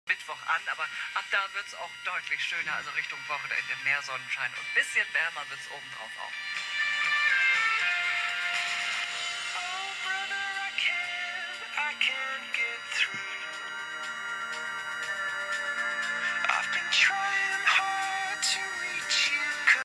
Radio pfeift/Störgeräusche
Das Fiepen ist im beiliegenden mp3-File (300kB)gut zu hören.